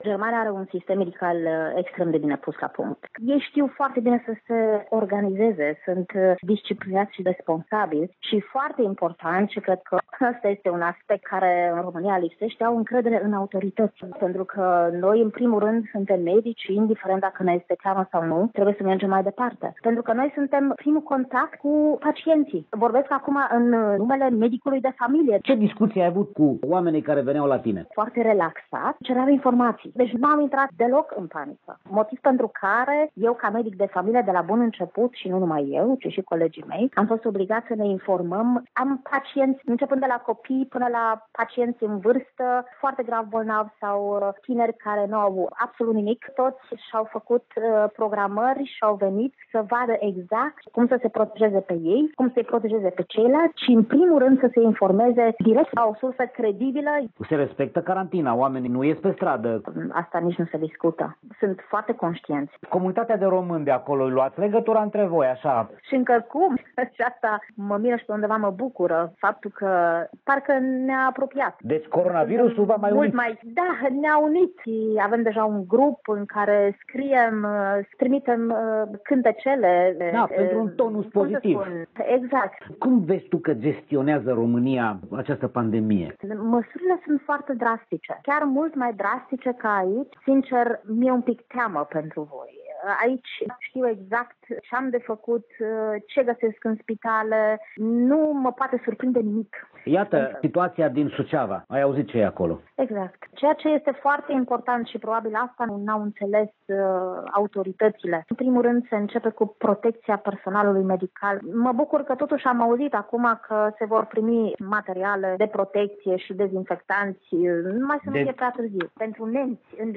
Exclusivitate: Interviu cu un medic de familie stabilit de 8 ani în Germania